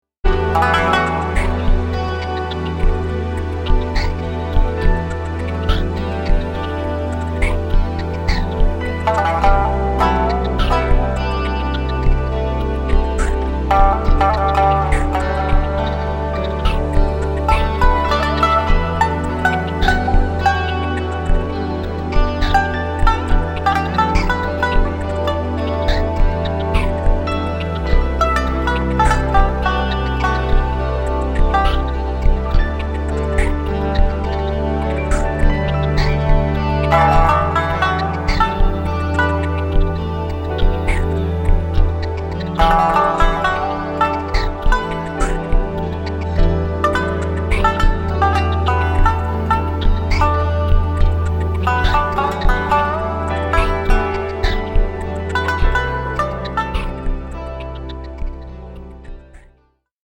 無限とも思えるほどの奥深い日本文化の神髄を洋楽器と共に表現し、日本人のDNAの中にある「和の心」を震わせる一枚。